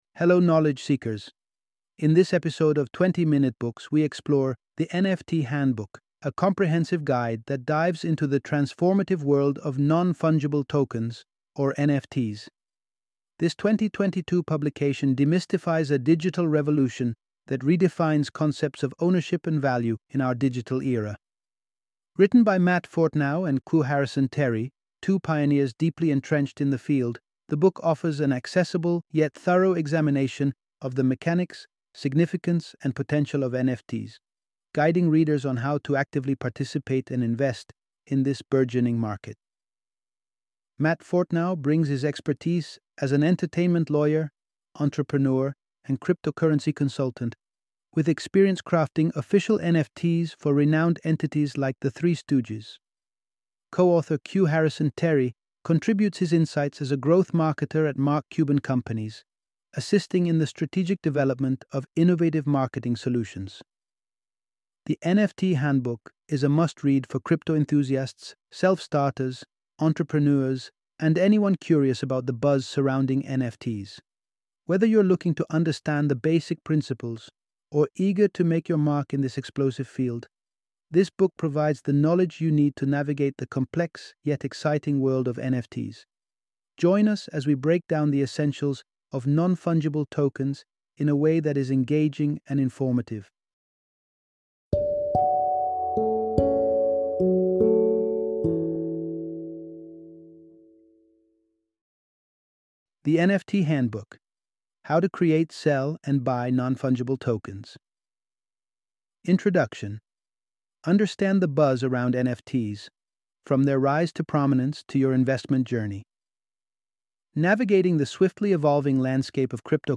The NFT Handbook - Audiobook Summary